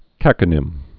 (kăkə-nĭm)